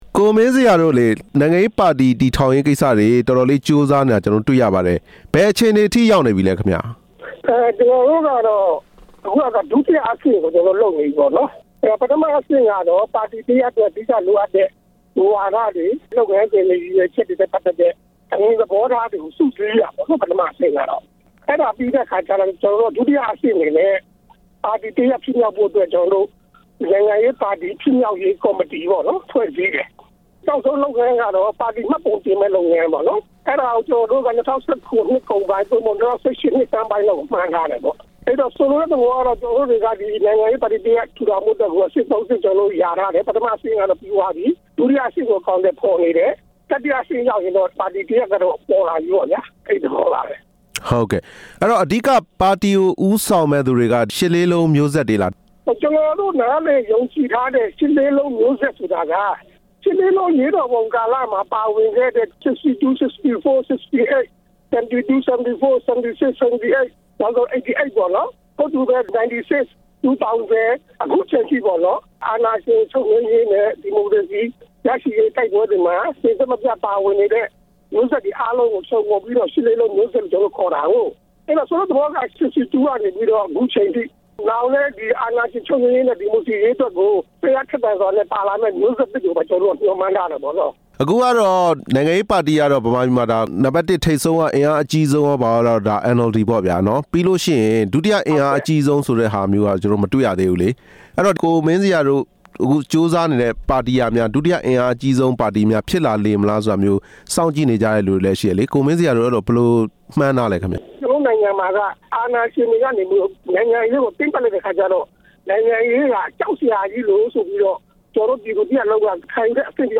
ဆက်သွယ်မေးမြန်းထားတာ